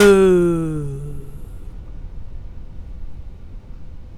puredata/resources/808_drum_kit/classic 808/Marie 808.wav at a362219e4a542cb6fe987cf91451e397294e5122